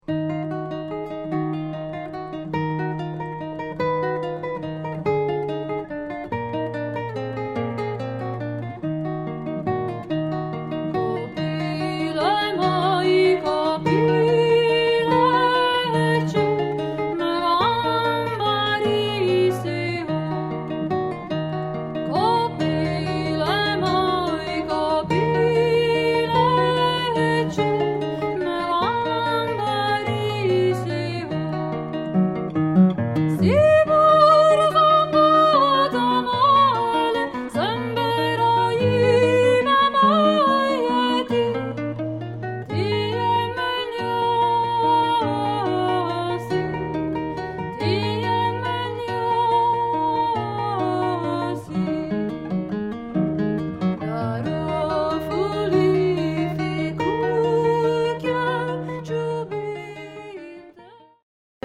Chitarra e canzoni popolari del sud